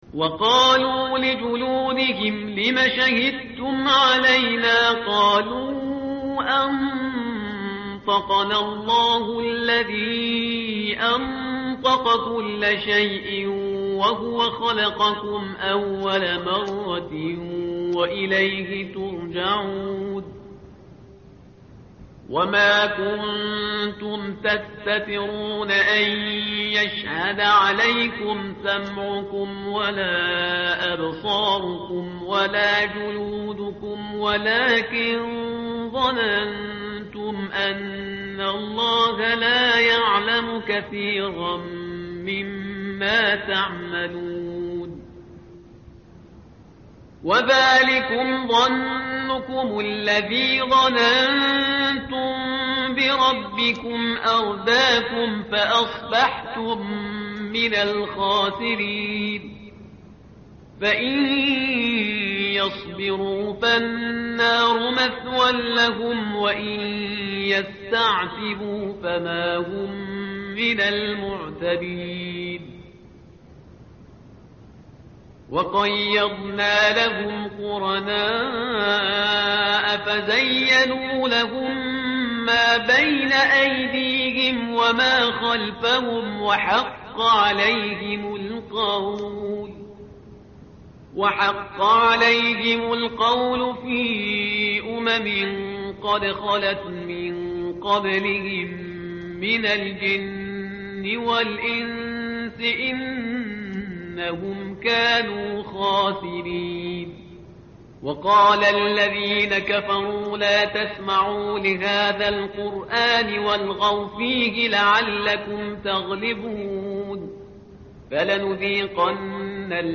متن قرآن همراه باتلاوت قرآن و ترجمه
tartil_parhizgar_page_479.mp3